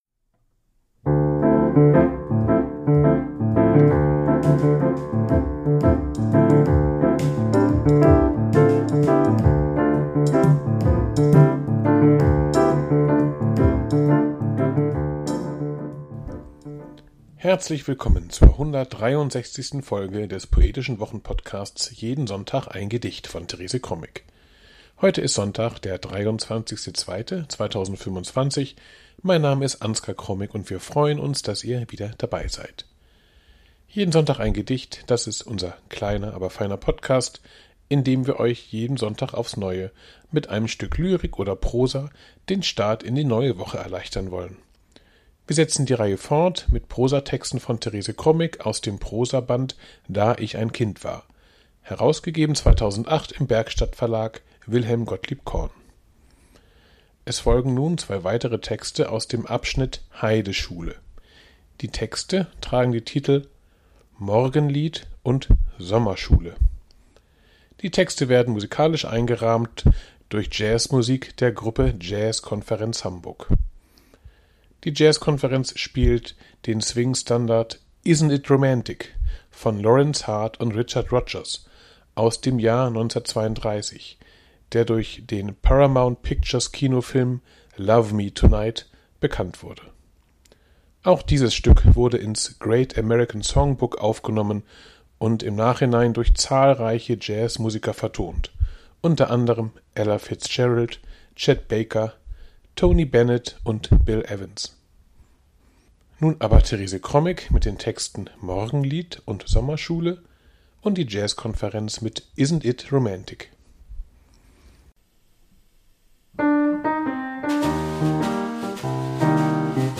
Die Jazzkonferenz spielt Swing-Standard "Isn't It